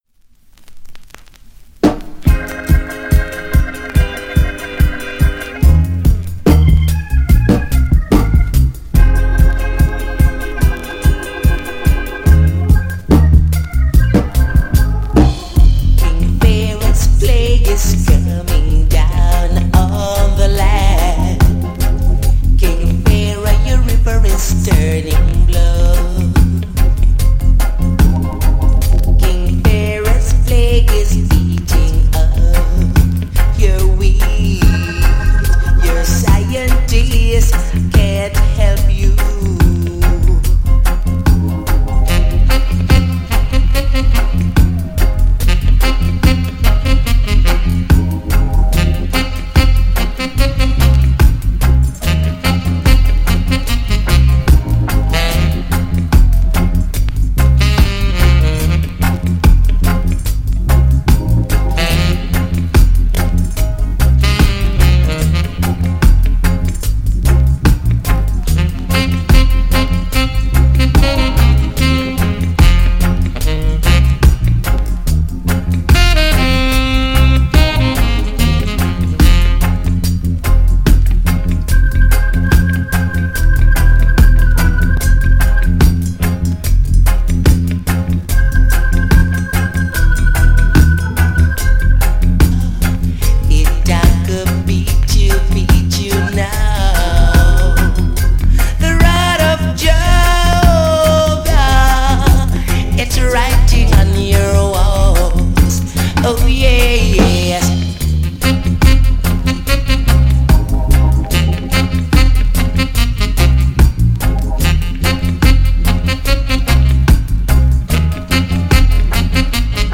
riding_forward_riddim.mp3